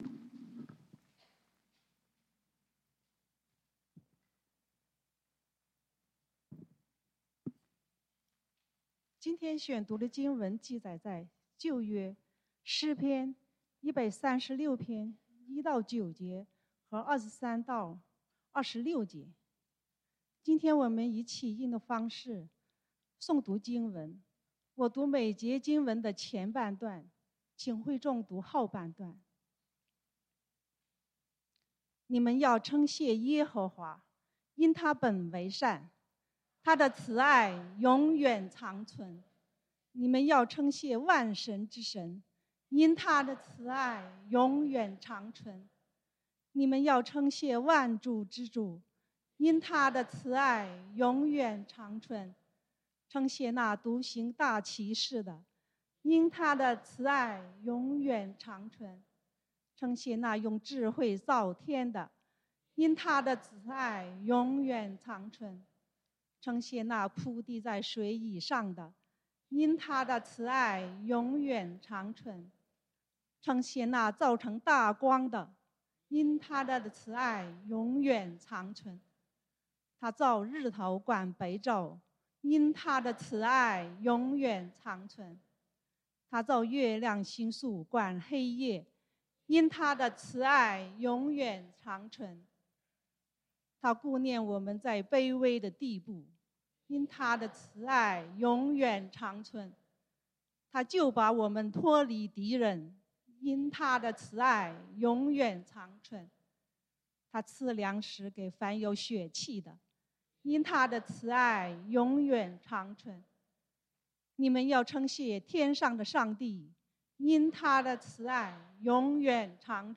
感恩節主日崇拜：感恩分享 (讀經經文：詩篇136:1-9, 23-26) | External Website | External Website